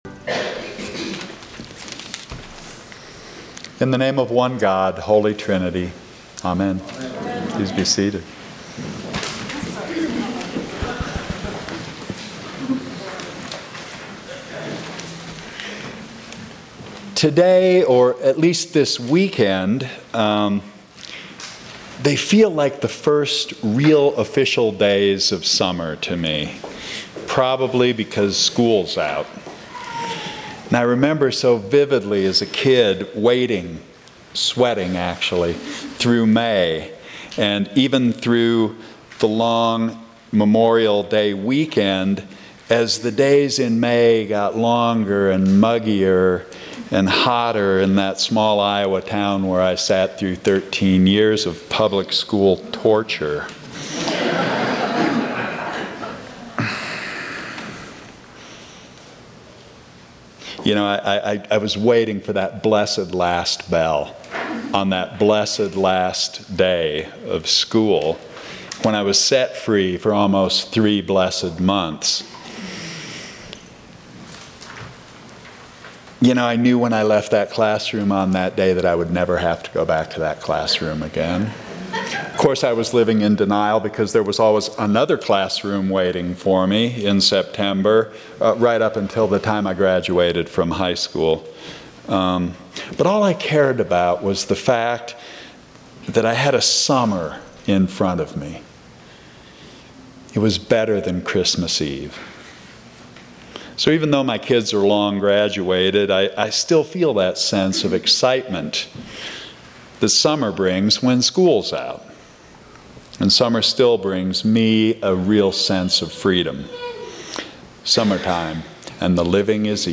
Sermons from St. John's Episcopal Church